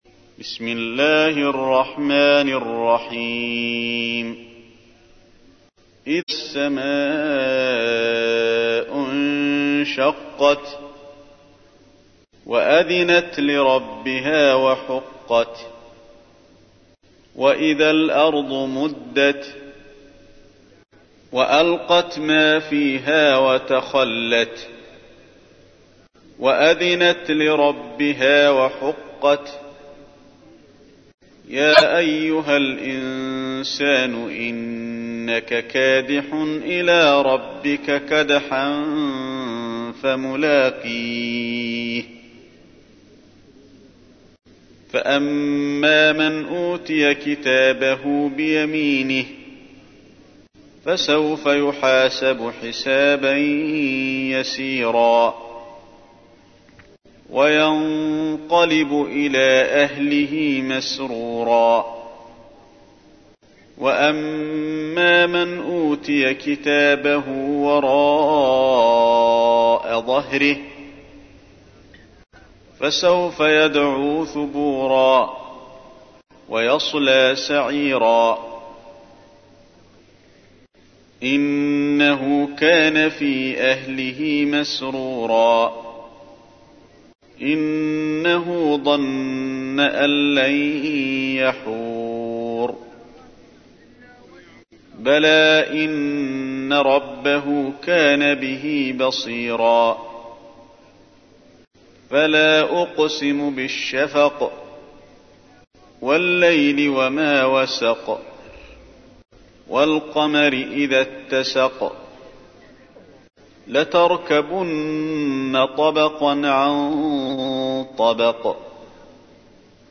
تحميل : 84. سورة الانشقاق / القارئ علي الحذيفي / القرآن الكريم / موقع يا حسين